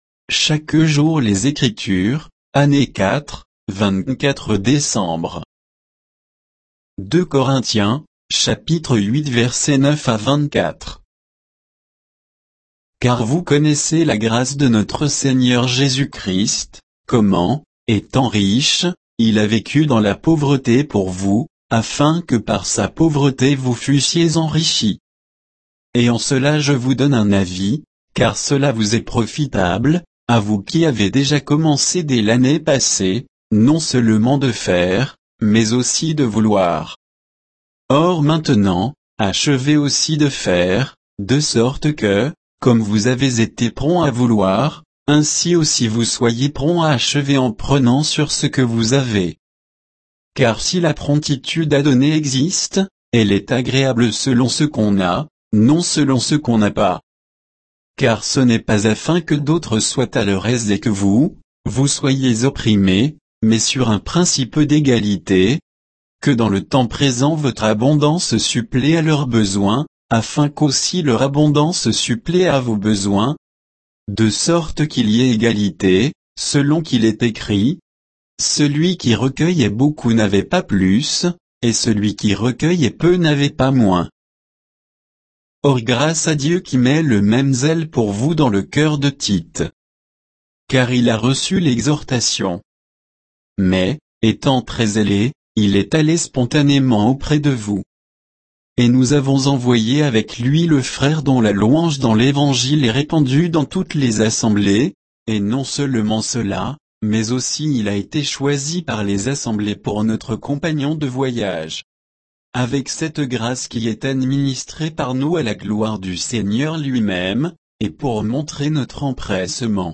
Méditation quoditienne de Chaque jour les Écritures sur 2 Corinthiens 8, 9 à 24